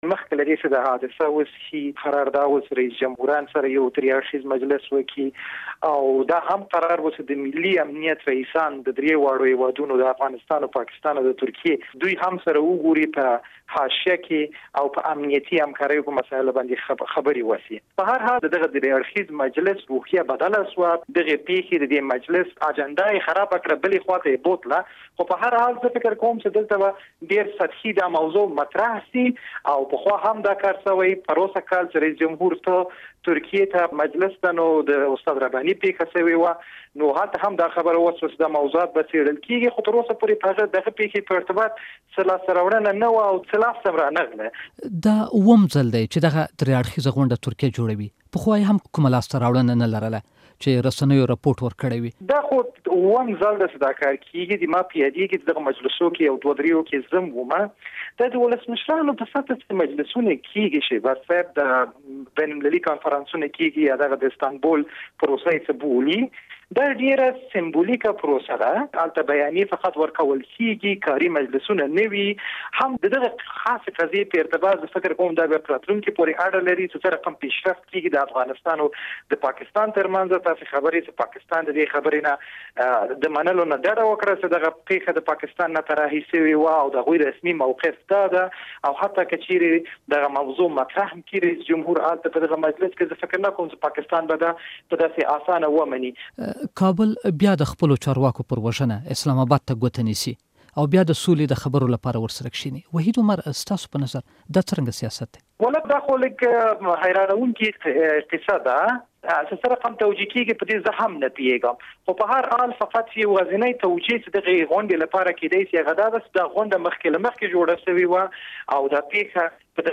مرکه